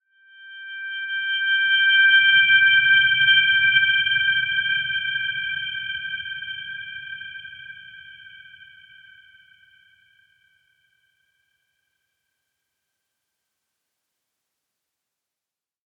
Dreamy-Fifths-G6-p.wav